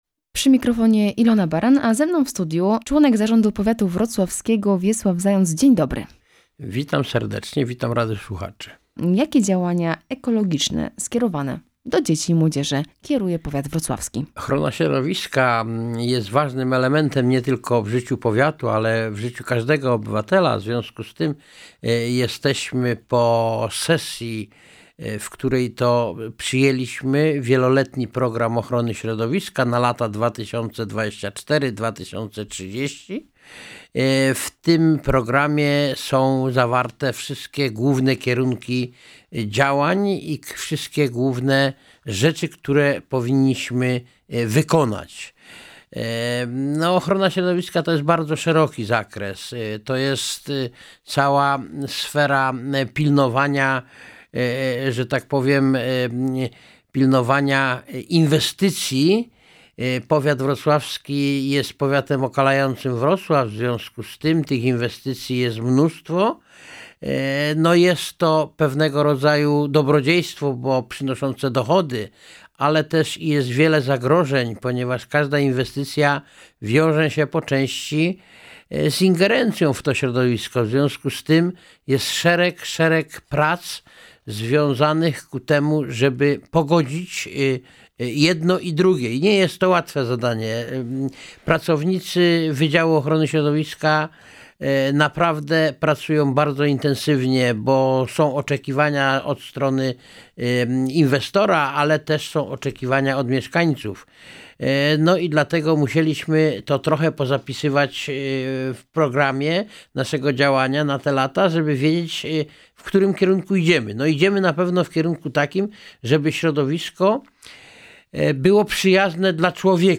Działania z zakresu Programu Ochrony Środowiska Powiatu Wrocławskiego na lata 2024–2030, inwestycje drogowe, także III forum seniorów Powiatu Wrocławskiego – to tematy poruszane w rozmowie z Wiesławem Zającem – Członkiem Zarządu Powiatu Wrocławskiego.